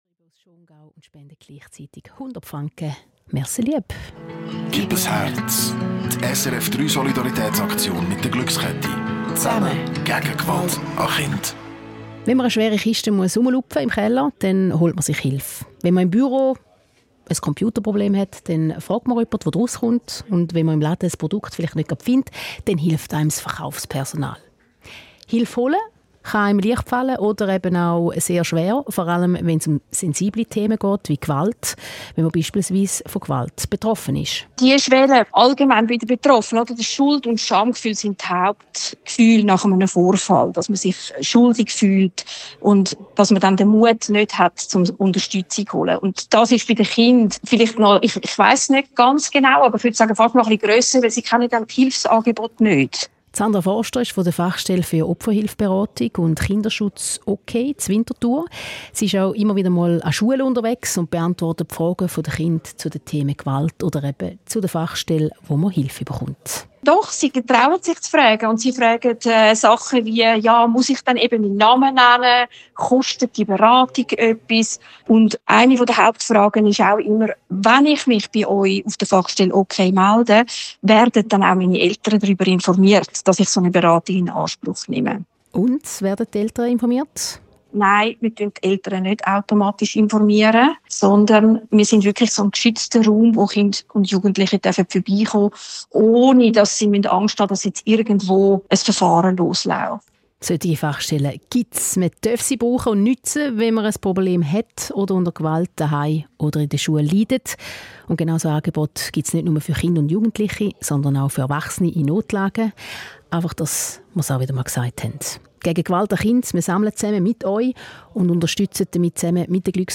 SRF3 Beitrag